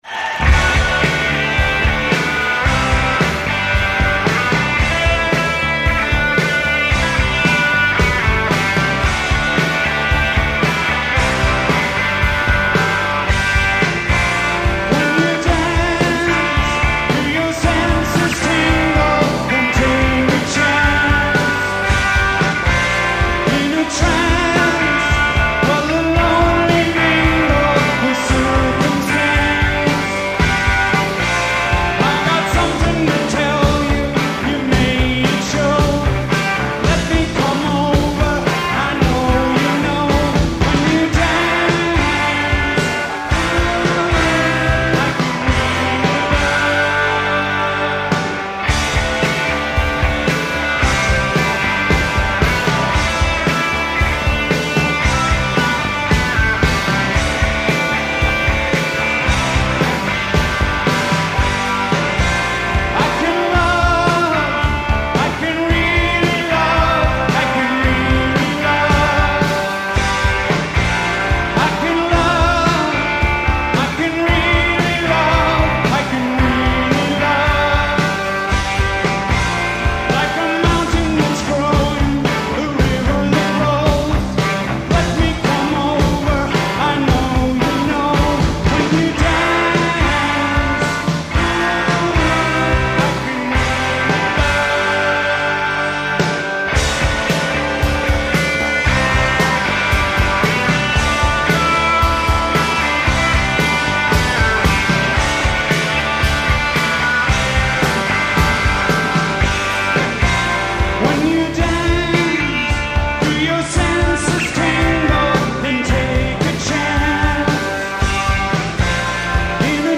This smoking performance is from San Francisco 1986.